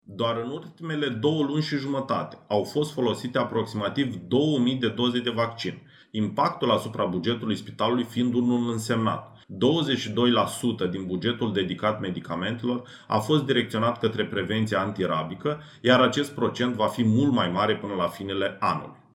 28aug-19-Medic-despre-rabie.mp3